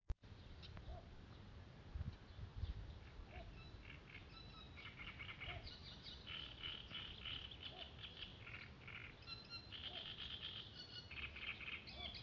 Mazais dumpis, Ixobrychus minutus
Administratīvā teritorijaRīga
StatussDzied ligzdošanai piemērotā biotopā (D)